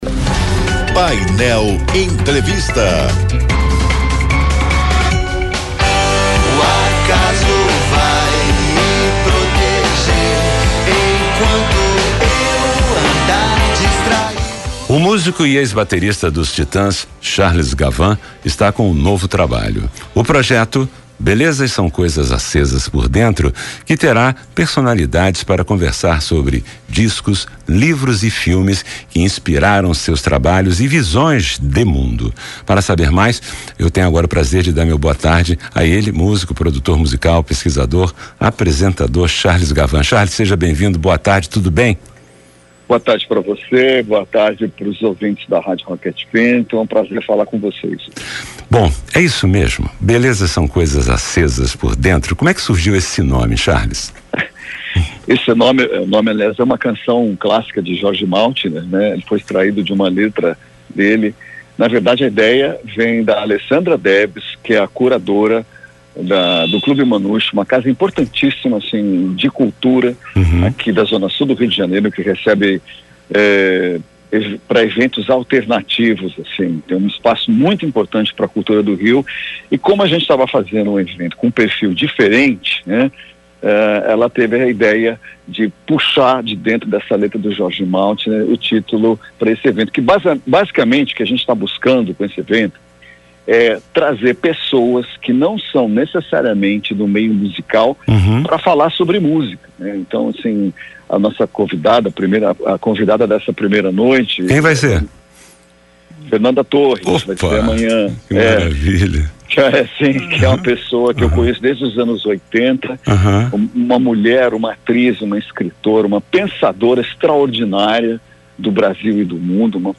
ENTREVISTA COM CHARLES GAVIN - 08.07.mp3